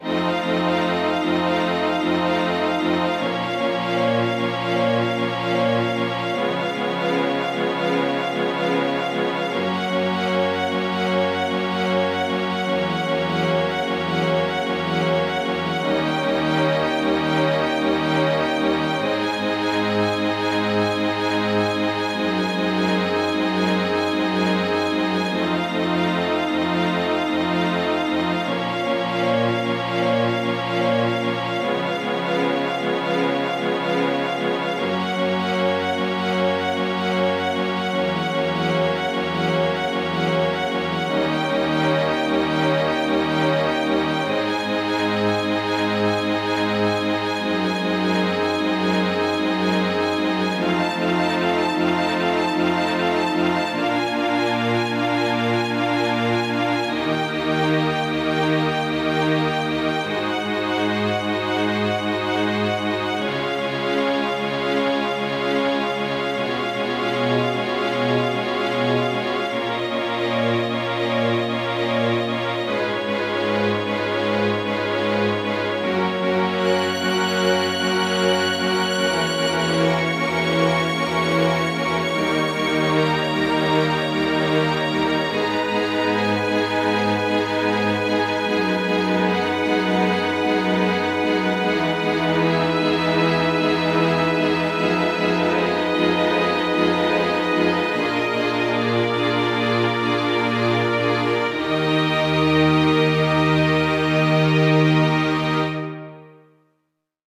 ogg(R) 不安 しっとり オーケストラ
雲がかかったような弦楽オーケストラ。